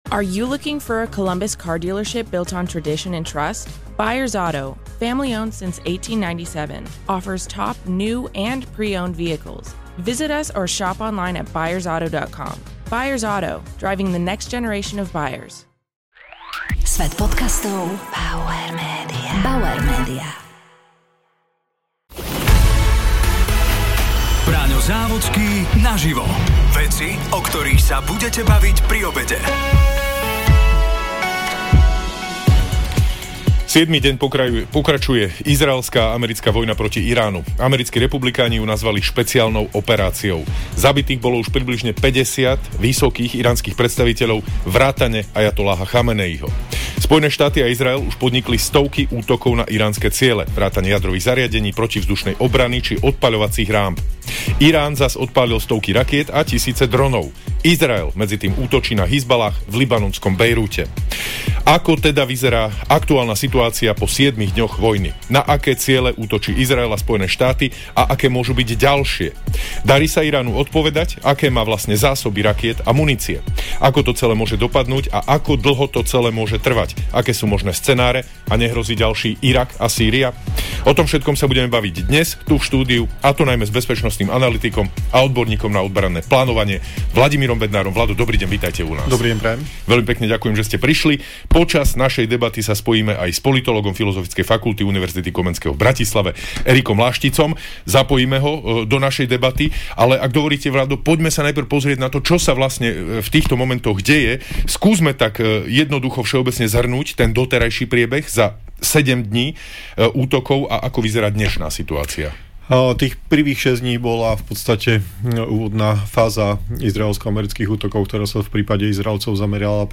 Braňo Závodský sa rozprával s bezpečnostným analytikom a odborníkom na obranné plánovanie